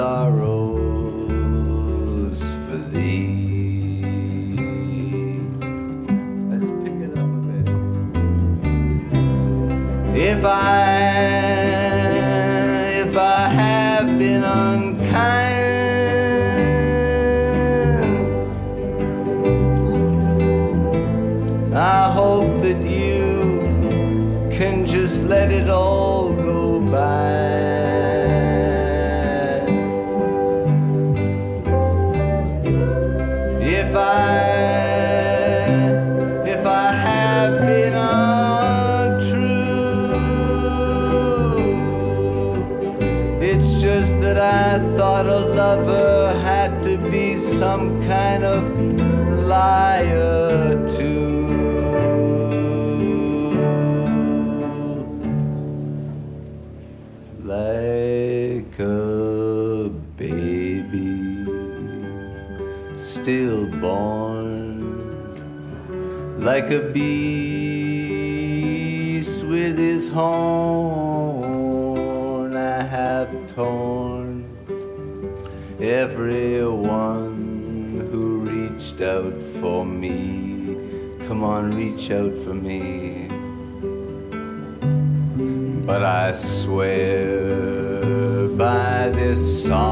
Folk, Singer-Songwriter, Contemporary Folk